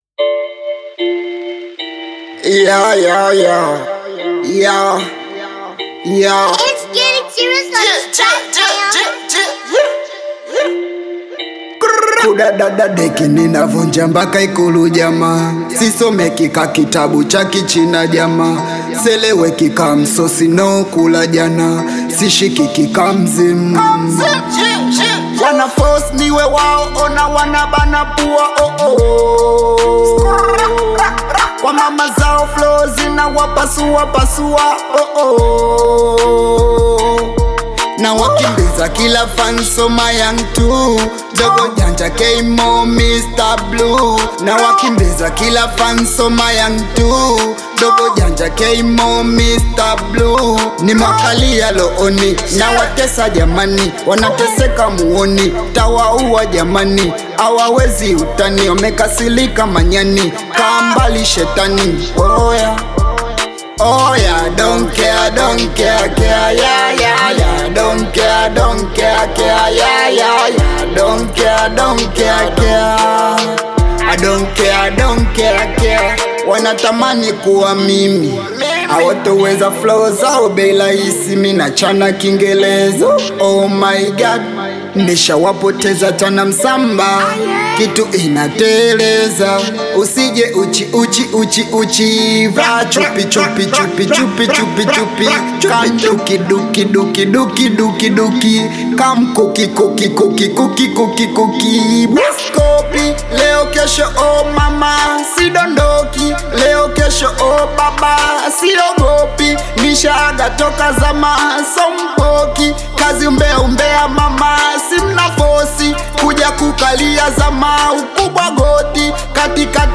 energetic Tanzanian hip-hop/Bongo Flava freestyle track